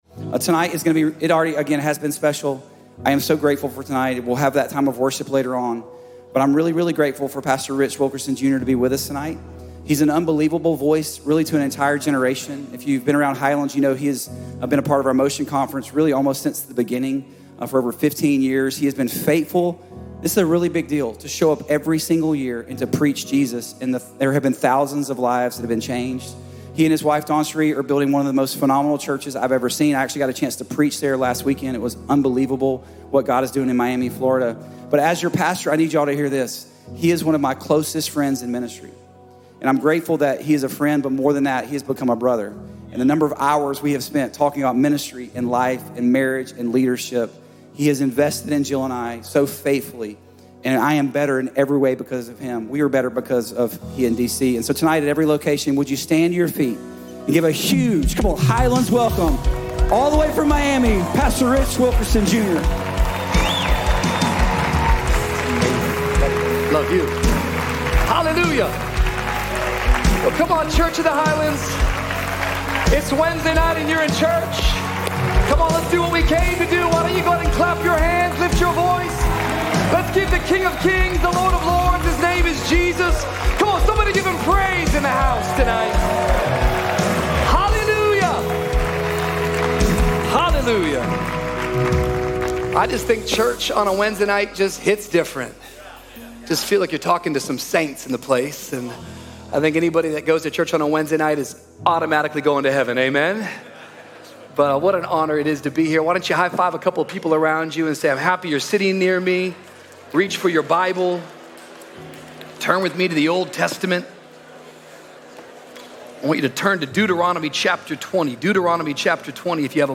Pastor Rich Wilkerson Jr. preaches from Deuteronomy 20 that believers can win their battles before they begin by refusing to let fear override faith, letting God's Word be louder than worry, maintaining godly order in life, and remembering that God fights for His people.